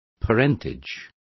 Complete with pronunciation of the translation of parentages.